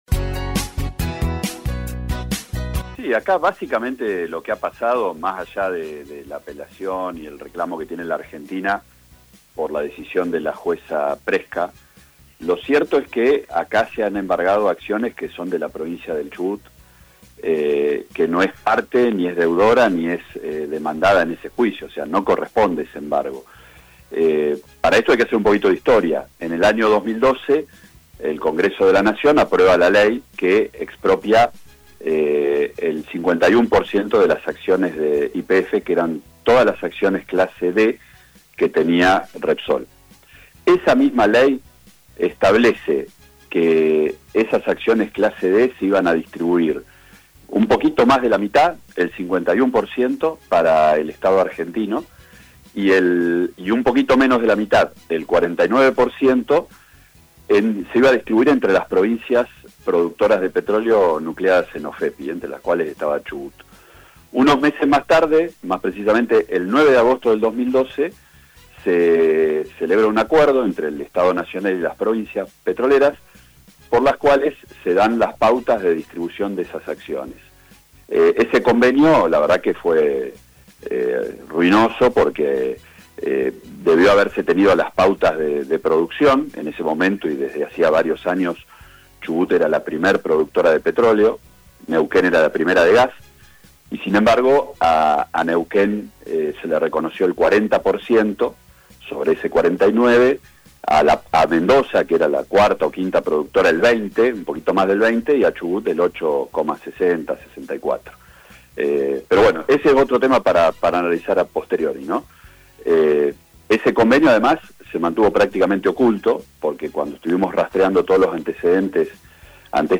Así lo explicaba el vice gobernador de Chubut, Gustavo Menna, al aire de RADIOVISIÓN: